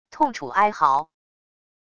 痛楚哀嚎wav音频